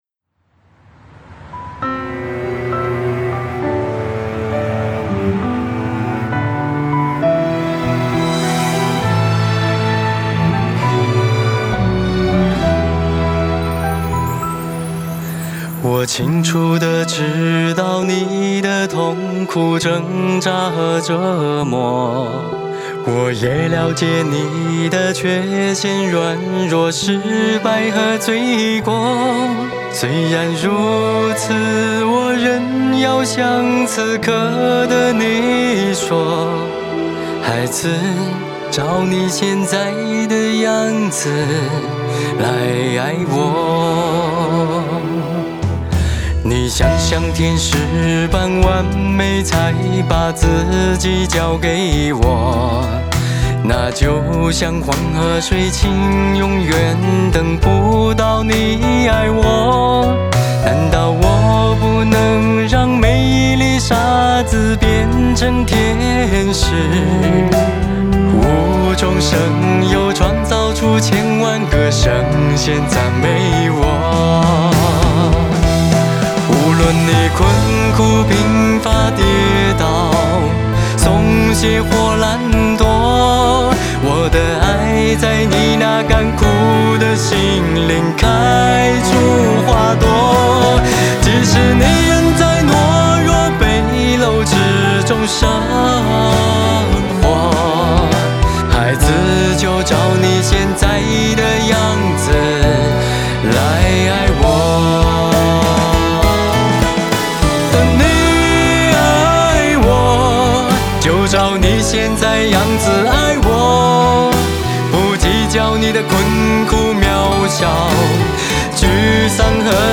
作品概况：这首歌曲创作于2024年5月，属于流行风格的教会音乐，易于广大教友传唱，简洁又不失严肃。
旋律节奏：歌曲采用传统86拍，以八分音符为主，副歌融入个别16分音符，节奏以行进方式层层推进，易于传唱和跟随。
旋律高潮：在副歌部分，旋律线条达到高潮，以高音域直接切入，起伏强烈，用以表达天主深切的劝诫以及浓烈的爱。
旋律装饰：歌曲的旋律中融入了一些装饰音，如滑音、颤音等，这些装饰音增加了旋律的生动和表现力。